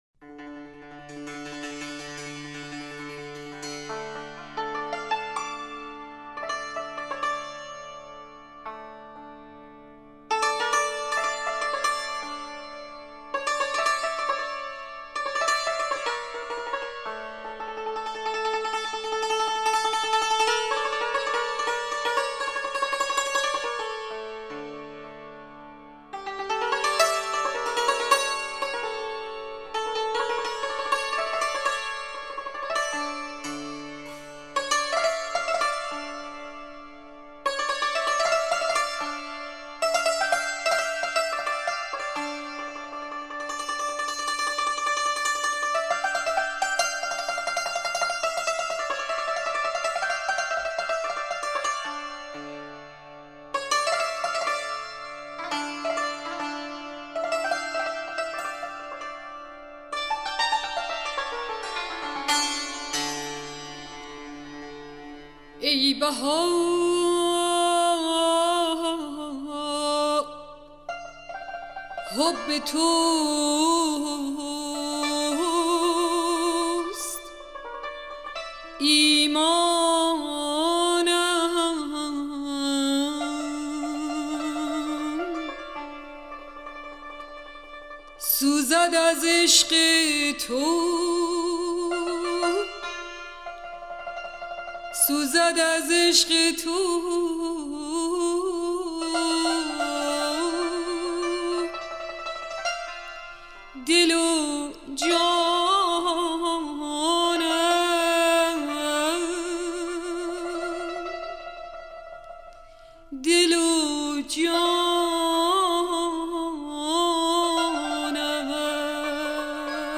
سرود - شماره 5 | تعالیم و عقاید آئین بهائی
مجموعه ای از مناجات ها و اشعار بهائی (سنتّی)